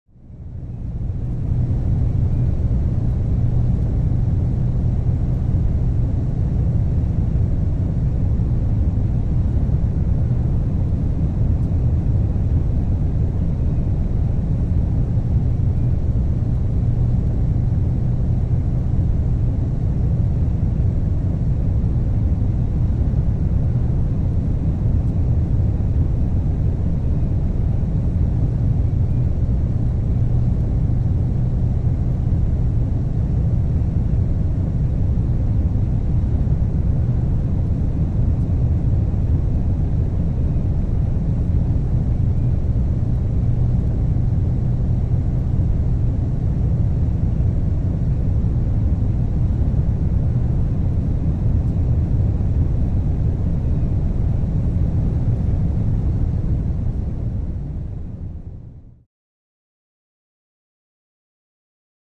Passenger Cabin Ambience, Smooth In Flight